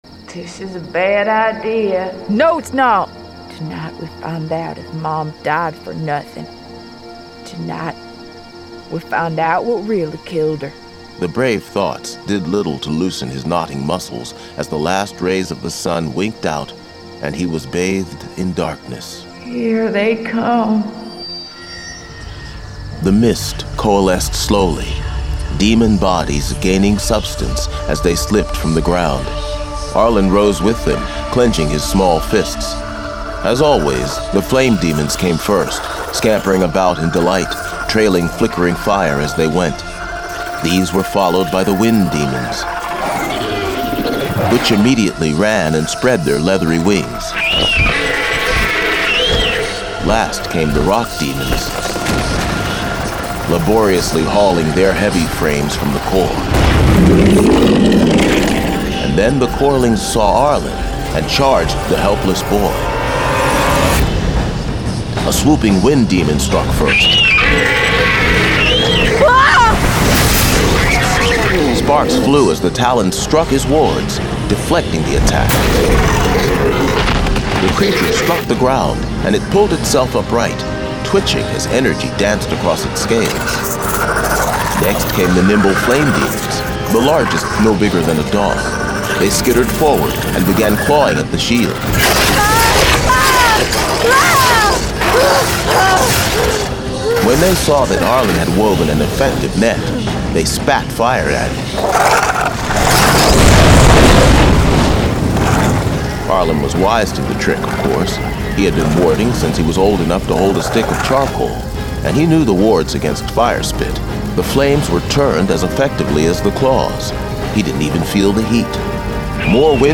Full Cast. Cinematic Music. Sound Effects.
Genre: Fantasy